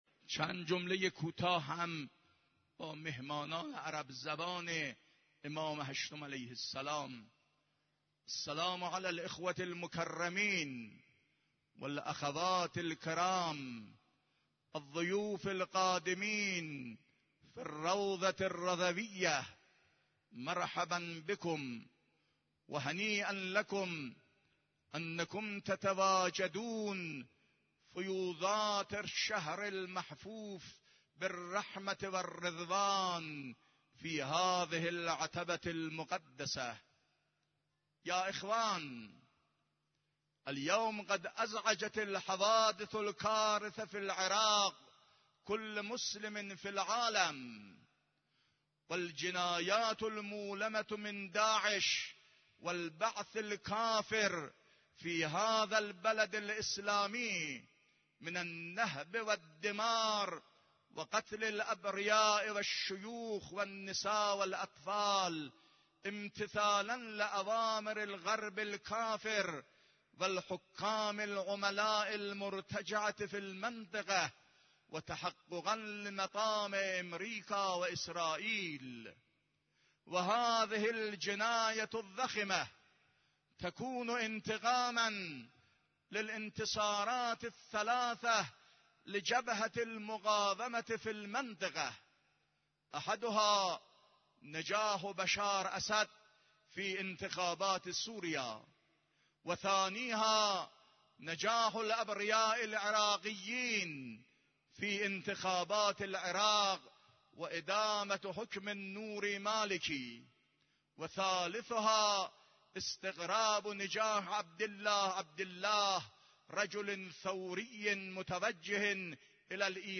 خطبه عربی نماز جمعه 6 تیرماه 93.mp3
خطبه-عربی-نماز-جمعه-6-تیرماه-93.mp3